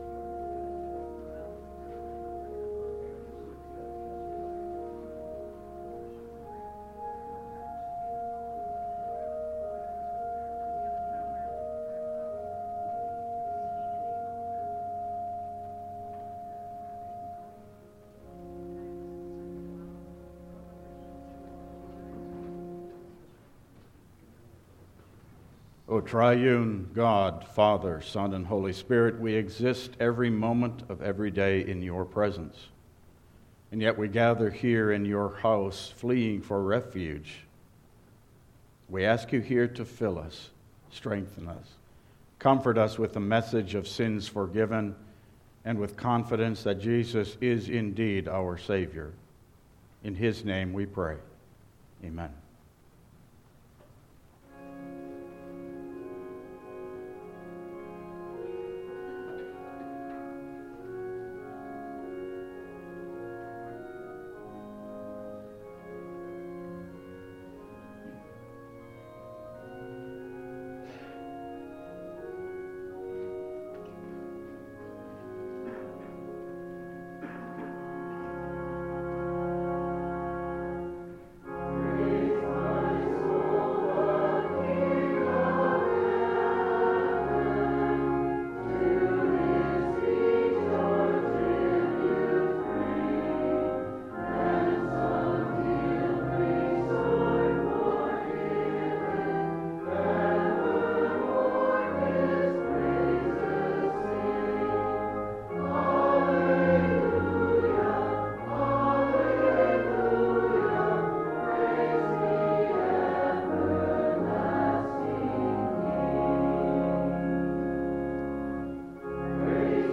Passage: 1 Timothy 1:12-17 Service Type: Regular Service